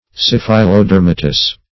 syphilodermatous - definition of syphilodermatous - synonyms, pronunciation, spelling from Free Dictionary
Search Result for " syphilodermatous" : The Collaborative International Dictionary of English v.0.48: Syphilodermatous \Syph`i*lo*der"ma*tous\, a. (Med.)